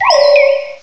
cry_not_litwick.aif